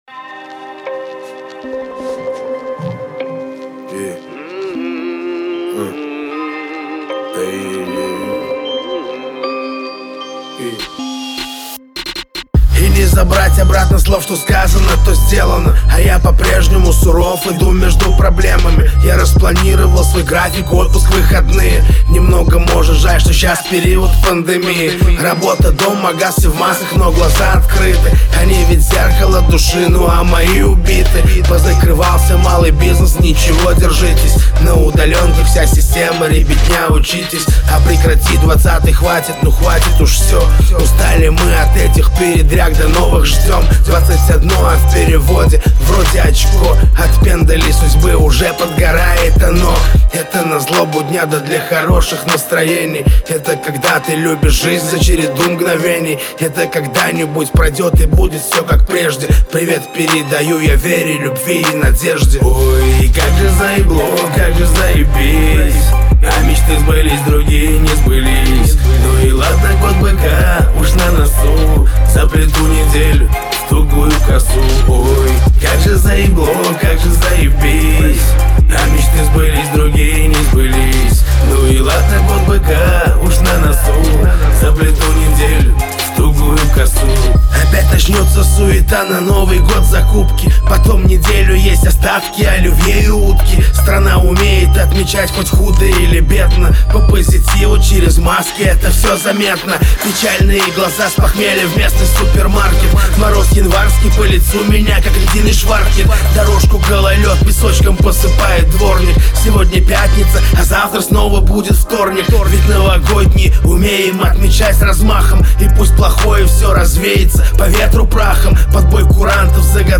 Новогодние песни